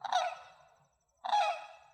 Guard Call | A sharp, single call expressing alarm.
White-naped-Crane-Alarm.mp3